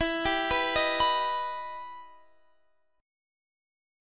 Listen to EmM7 strummed